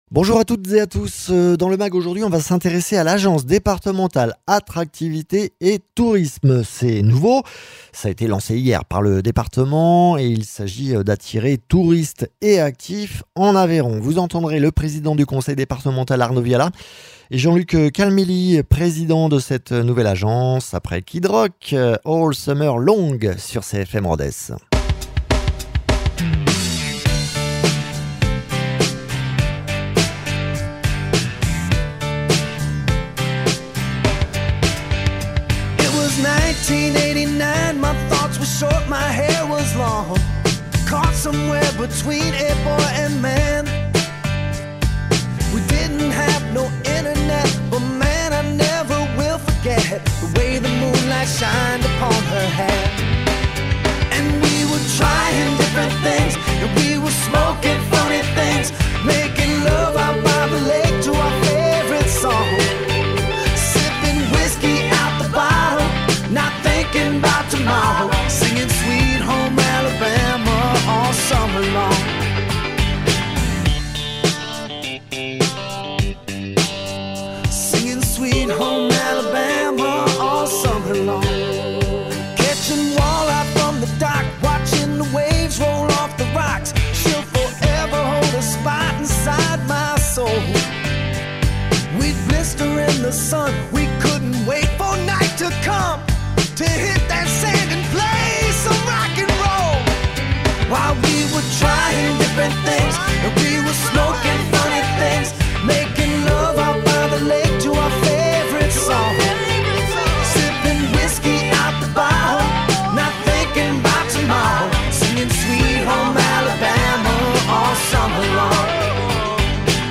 Invité(s) : Arnaud Viala, Président du conseil départemental